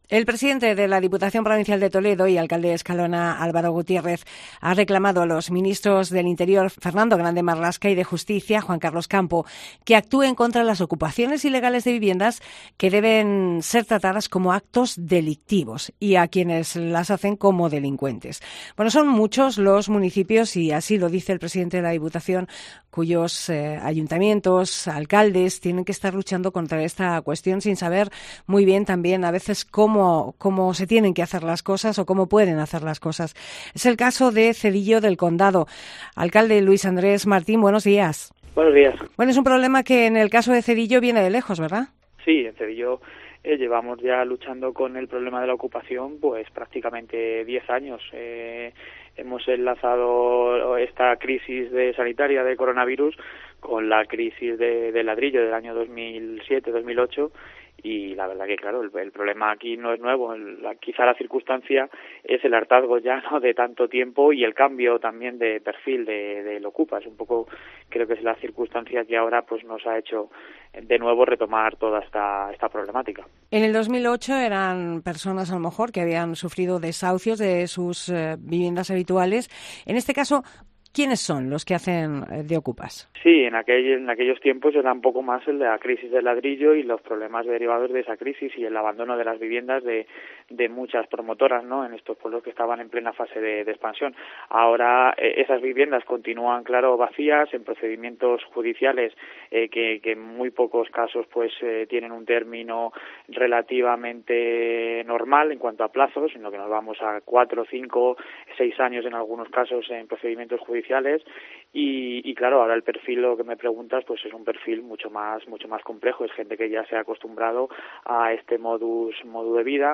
Entrevista al alcalde de Cedillo del Condado